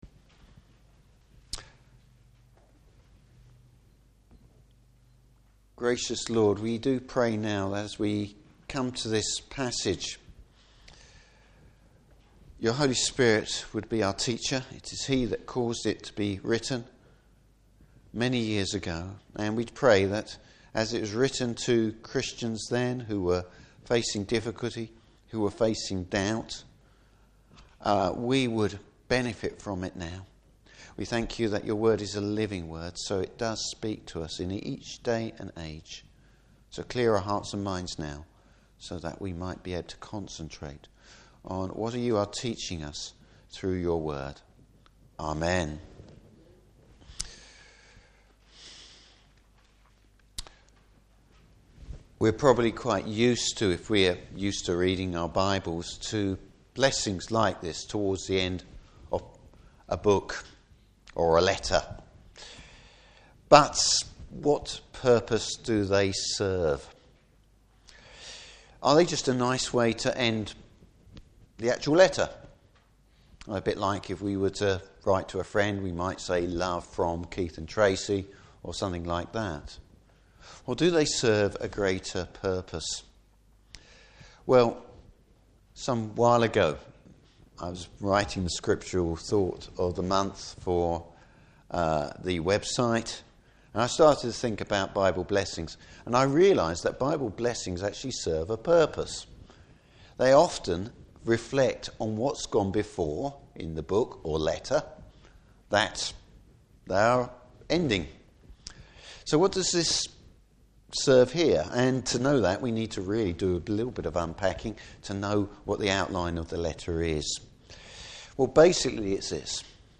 Service Type: Easter Day Morning Service.